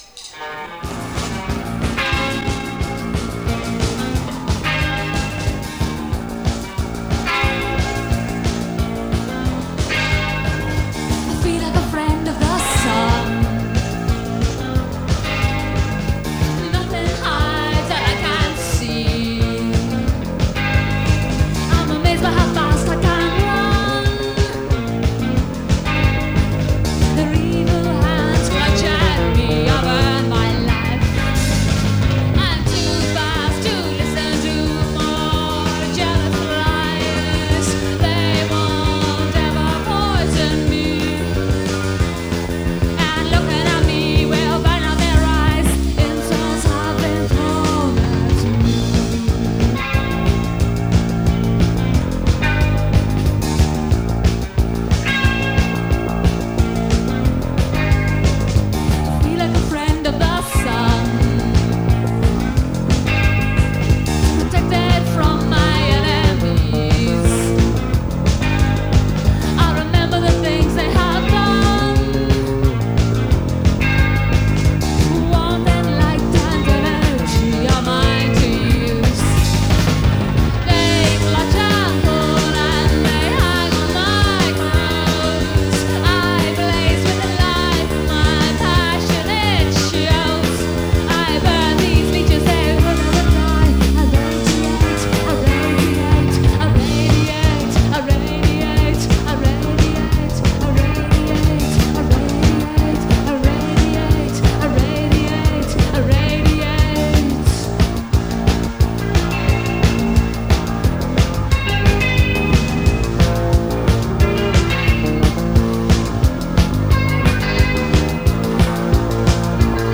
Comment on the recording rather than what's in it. Recorded at Amersfoort in the Netherlands